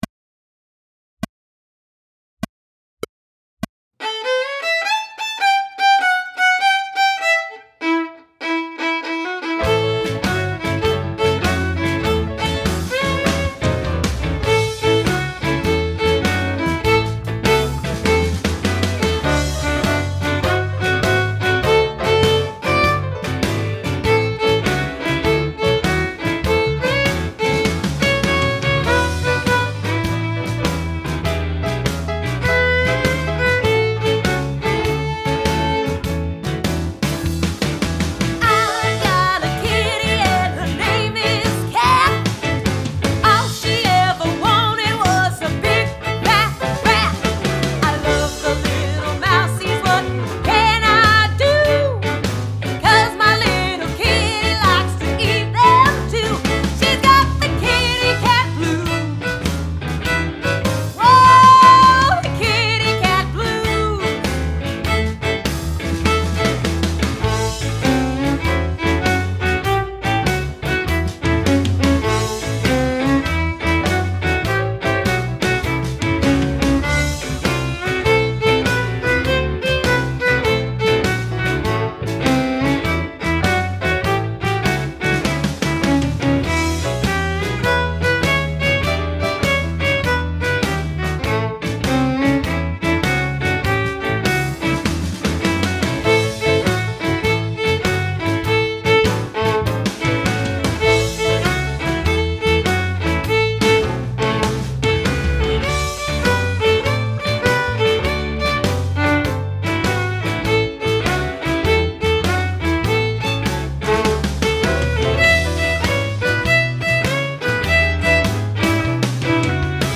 Voicing: Viola